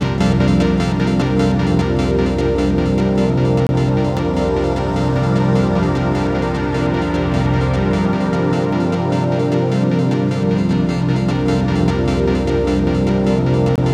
SEQ PAD01.-R.wav